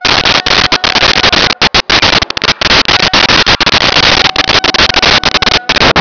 Sfx Rusty Creaks Loop
sfx_rusty_creaks_loop.wav